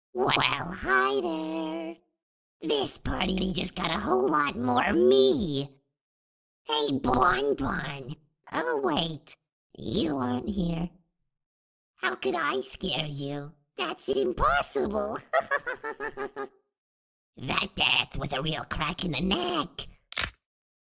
Helpy's Voice Lines 🐻| Parte sound effects free download